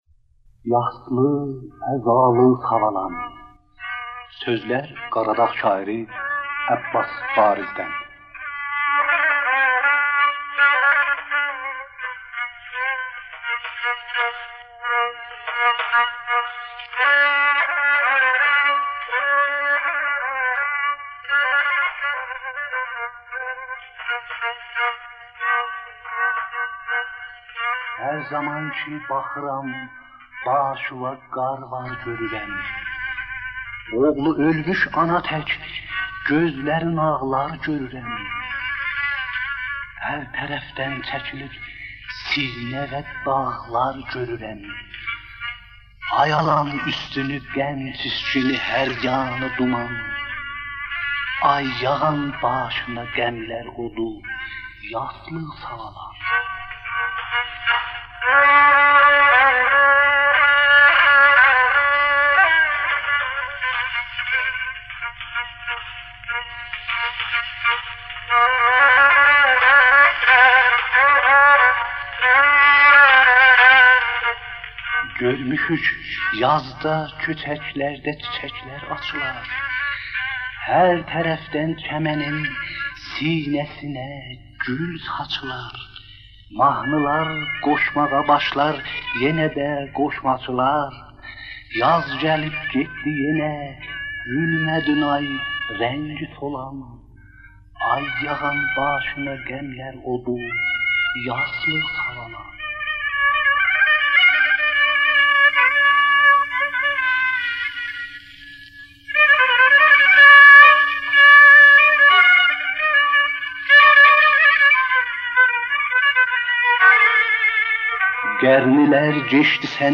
تبریز رادیوسونون دانشسرا خیابانینداکی بیناسی‌نین ایکی نومره‌لی استودیوسوندا (کیچیک استودیودا)